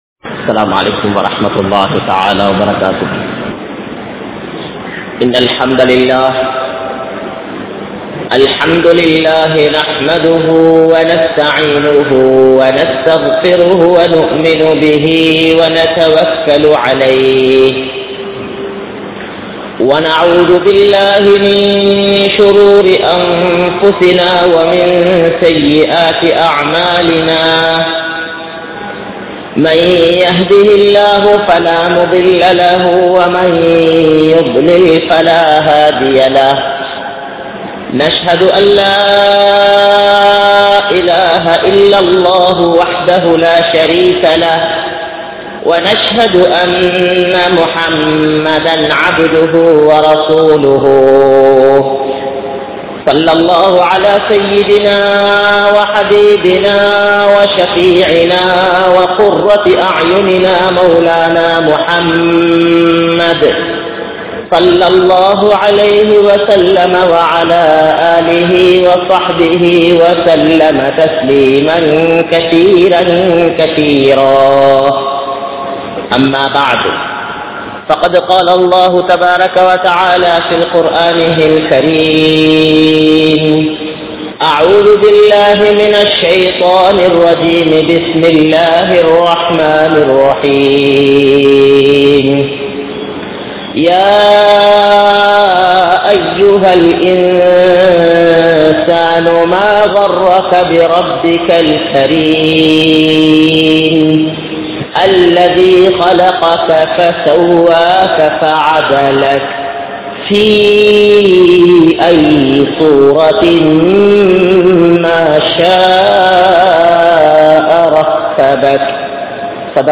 Rahasiyamaana Paavangalai Vittu Vidungal (ரகசியமான பாவங்களை விட்டு விடுங்கள்) | Audio Bayans | All Ceylon Muslim Youth Community | Addalaichenai
Al Masjidhul Minhaj